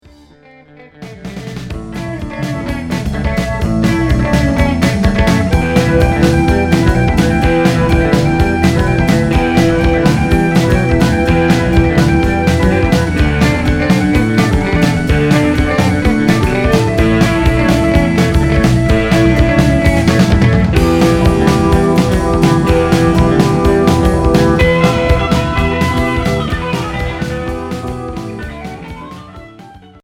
Pop rock Unique 45t retour à l'accueil